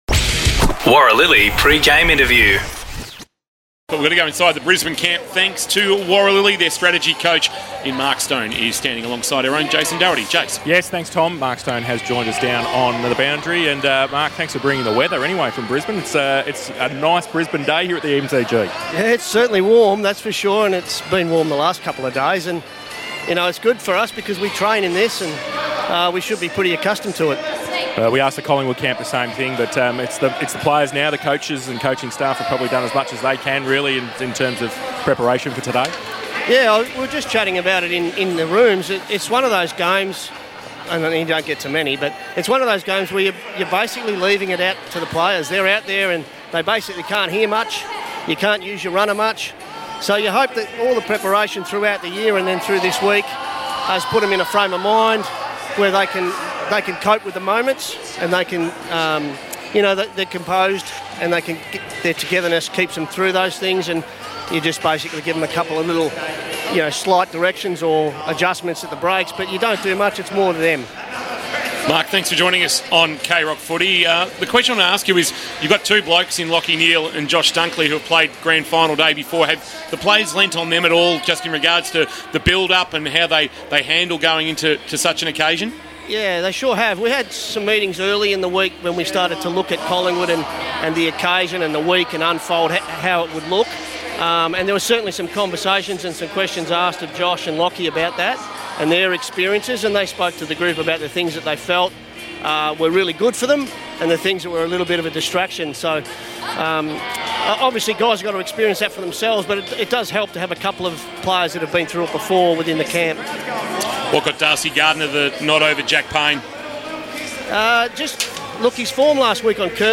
2023 - AFL - GRAND FINAL - COLLINGWOOD vs. BRISBANE: Pre-match interview